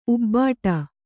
♪ ubbāṭa